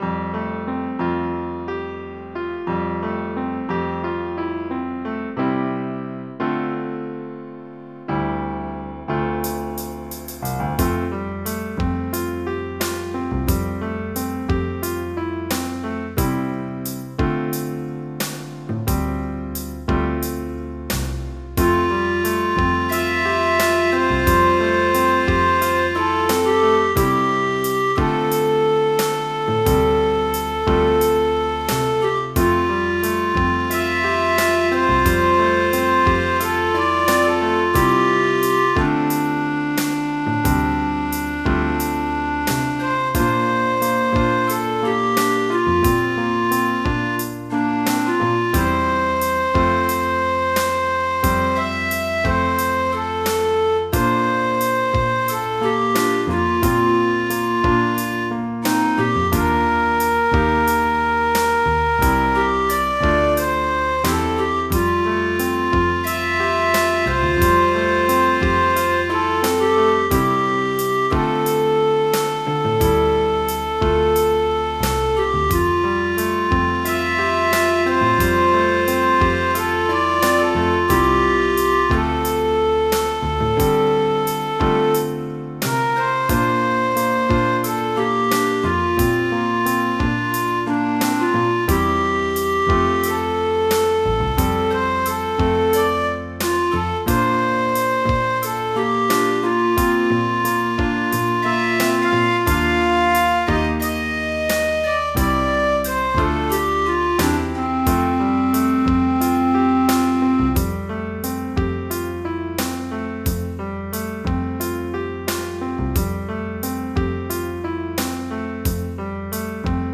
MIDI Music File
Type General MIDI
BALLADE.mp3